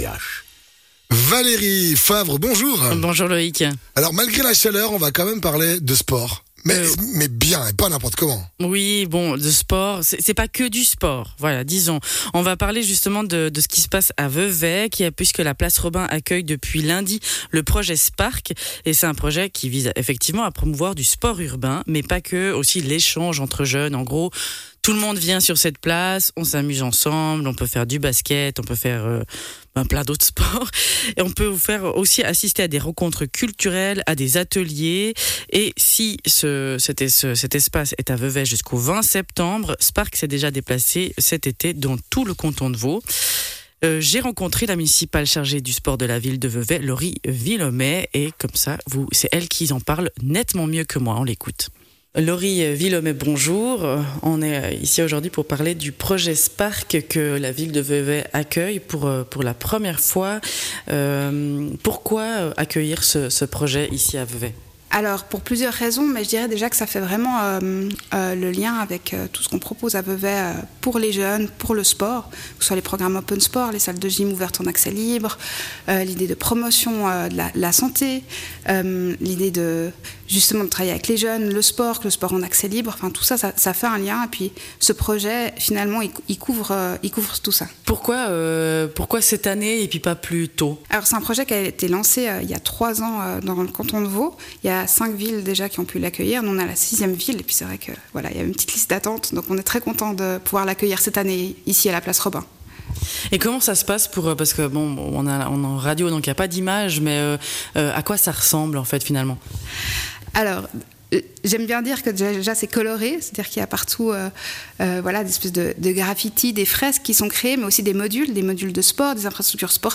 Intervenant(e) : Laurie Willommet, municipale veveysanne chargé du sport